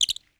Audio / SFX / Characters / Voices / PhoenixChick / PhoenixChick_08.wav